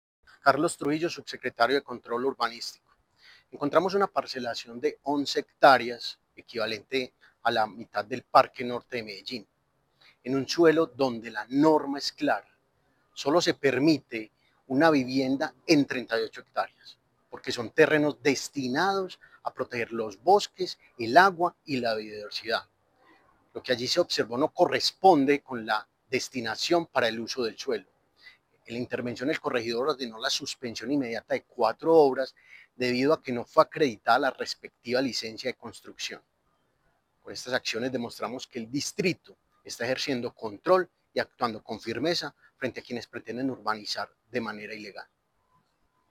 Declaraciones subsecretario de Control Urbanístico, Carlos Trujillo Vergara
Declaraciones-subsecretario-de-Control-Urbanistico-Carlos-Trujillo-Vergara.mp3